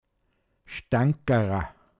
Wortlisten - Pinzgauer Mundart Lexikon